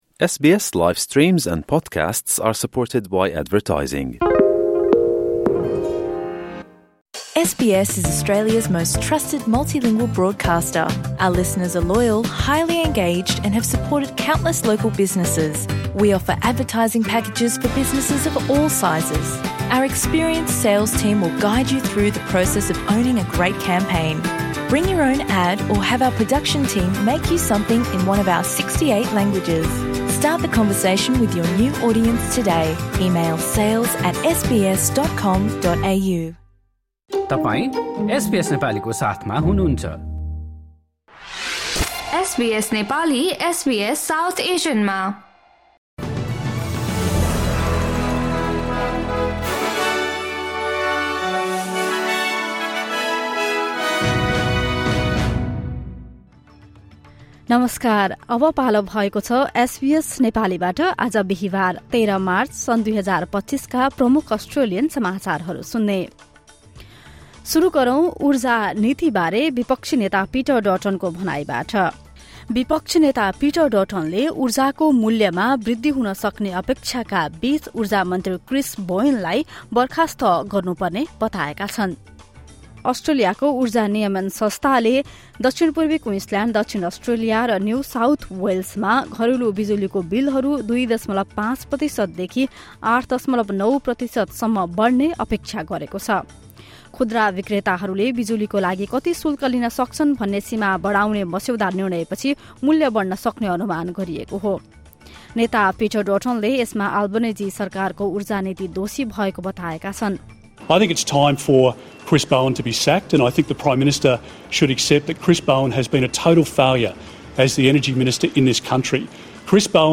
SBS Nepali Australian News Headlines: Thursday, 13 March 2025